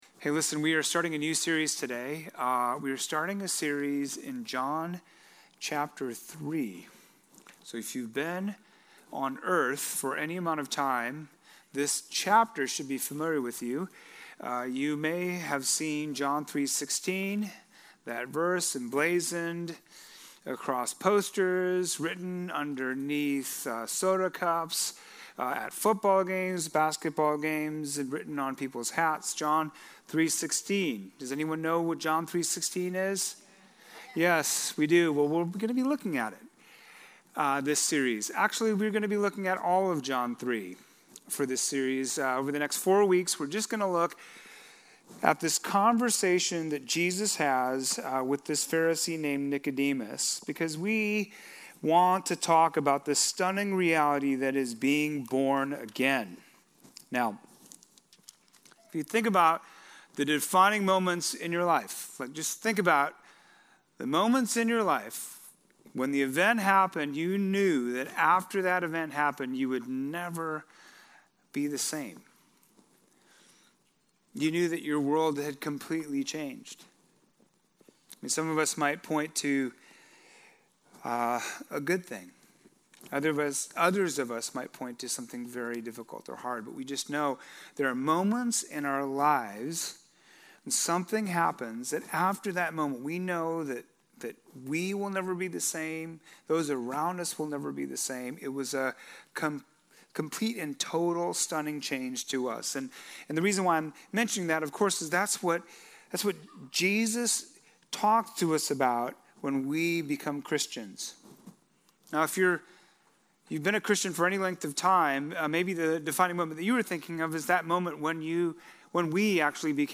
brings today's message. John 3:1-3; John 1:12-13; Ephesians 2:8-9; John 19:39